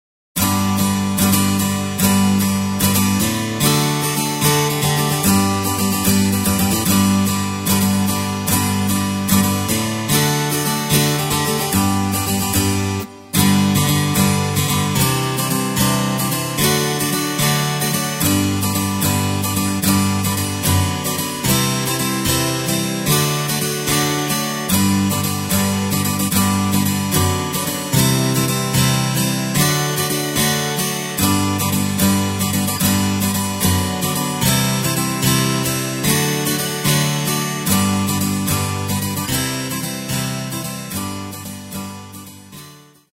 Tempo:         148.00
Tonart:            G
Playback mp3 Demo